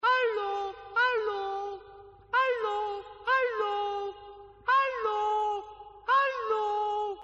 короткие
смешной голос